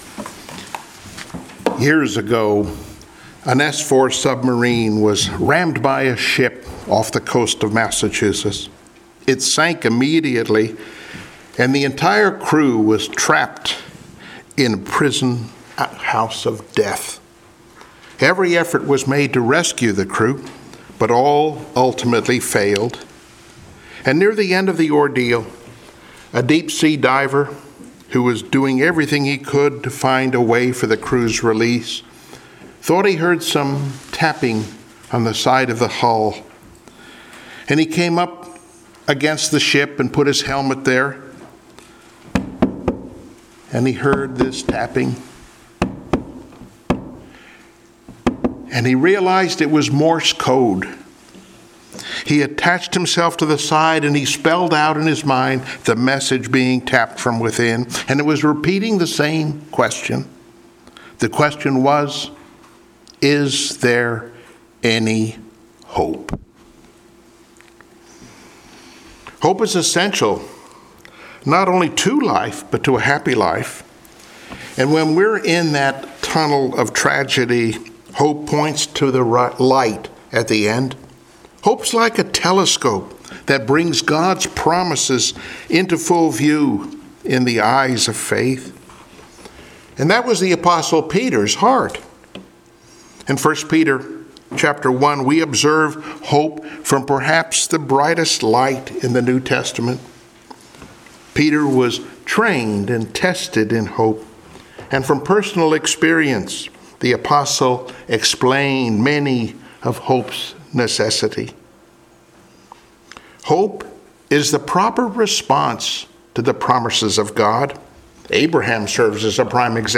1 Peter Passage: 1 Peter:1:8-9 Service Type: Sunday Morning Worship Download Files Notes Bulletin « “The Dynamite Church” “Walking in Harmony” »